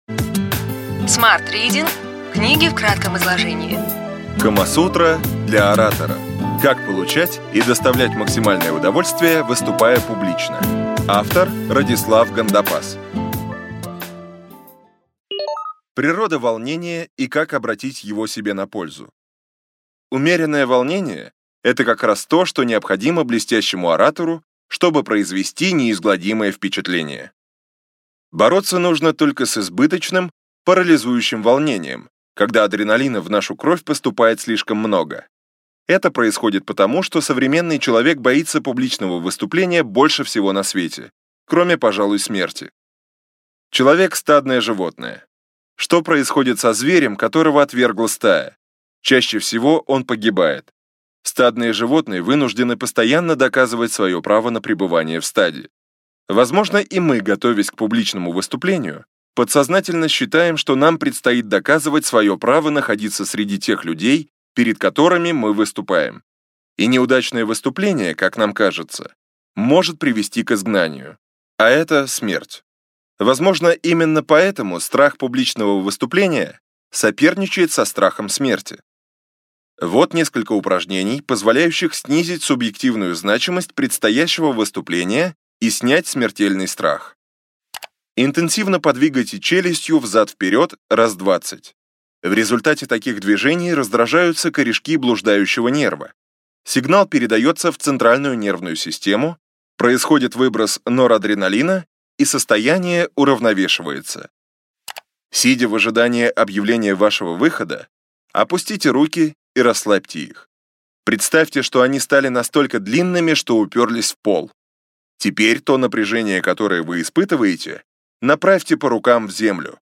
Аудиокнига Ключевые идеи книги: Камасутра для оратора. 10 глав о том, как получать и доставлять максимальное удовольствие, выступая публично.